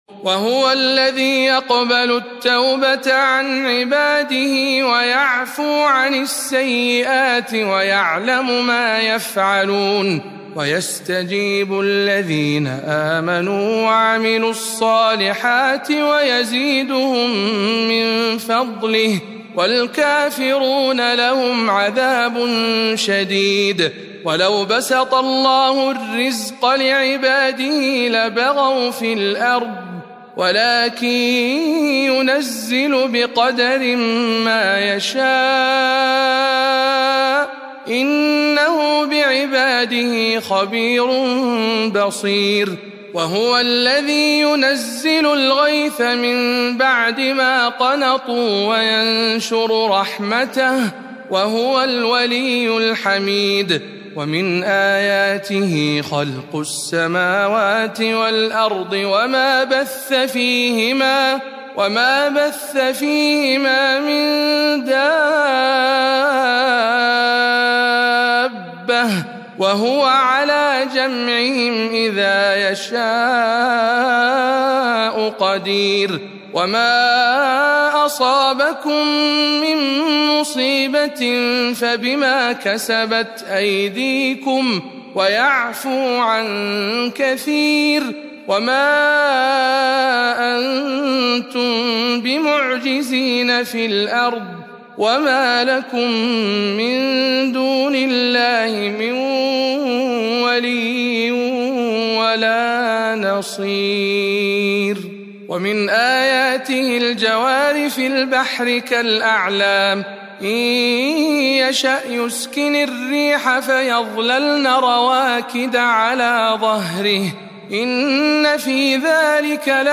تلاوة مميزة من سورة الشورى